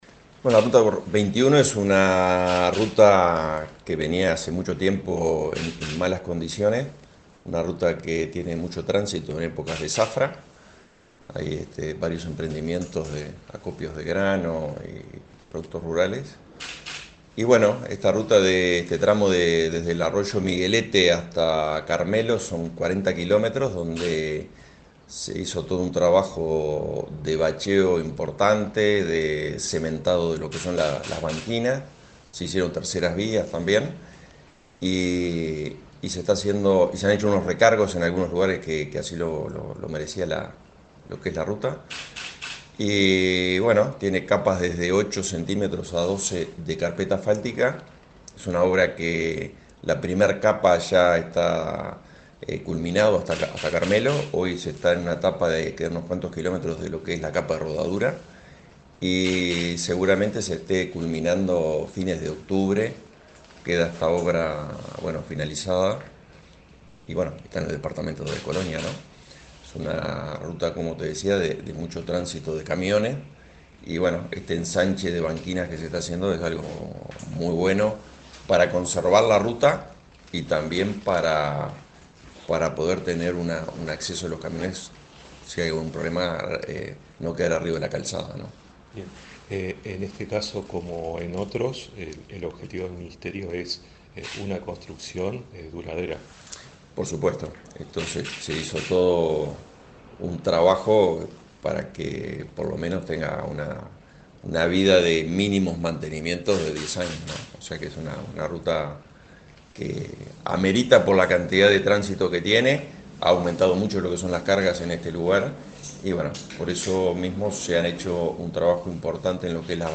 Entrevista al director nacional de Vialidad, Hernán Ciganda